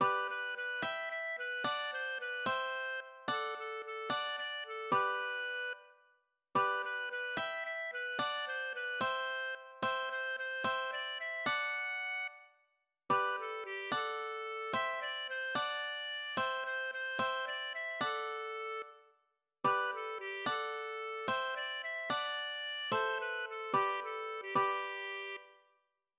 Zwischengesang